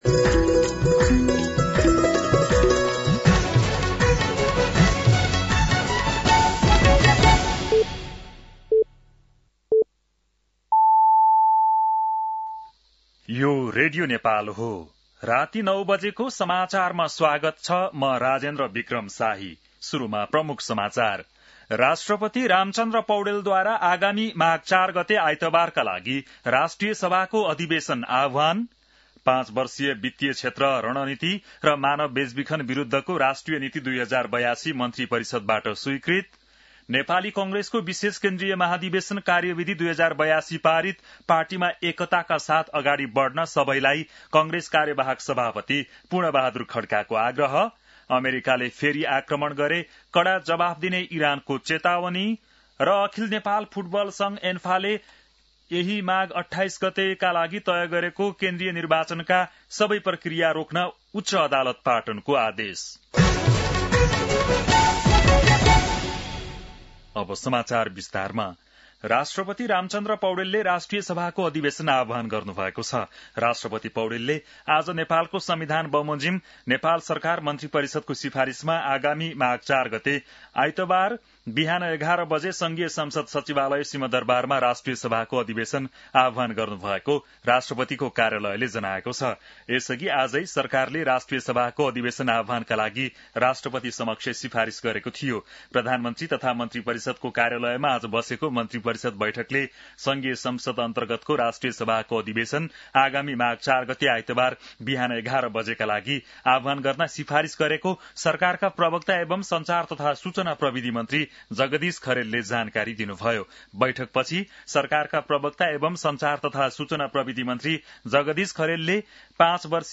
बेलुकी ९ बजेको नेपाली समाचार : २८ पुष , २०८२
9-PM-Nepali-NEWS-9-28.mp3